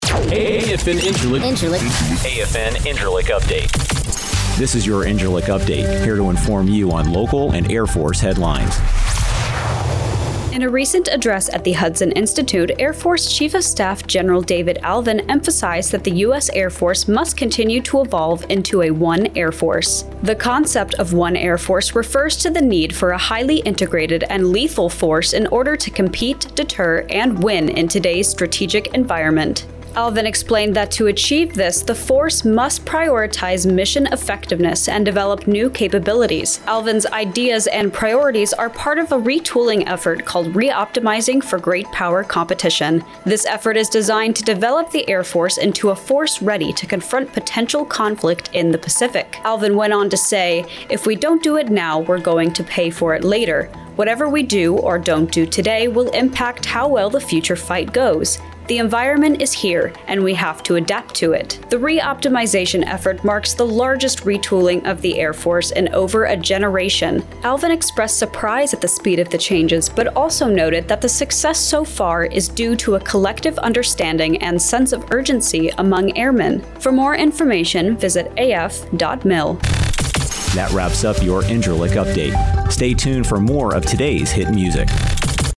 AFN INCIRLIK RADIO NEWSCAST: Gen Allvin Calls for "One Air Force"